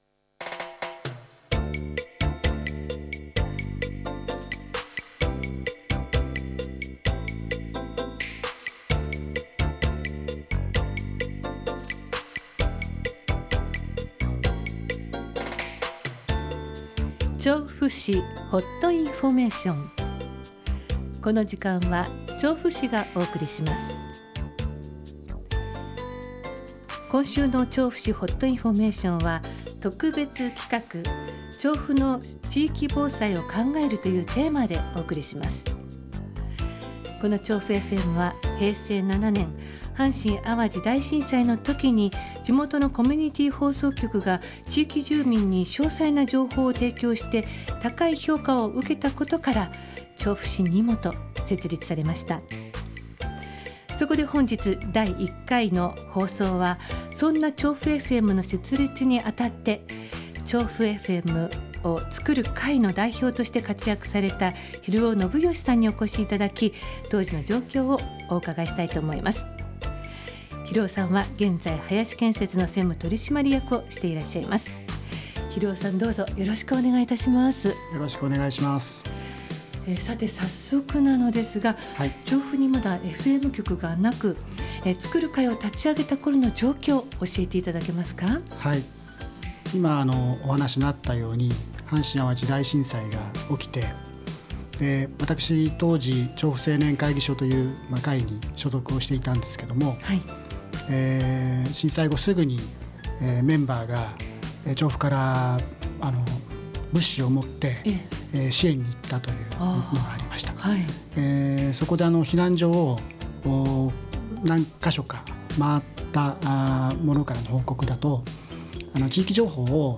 当日の放送の様子をアップロードしましたので，是非，お聞きください。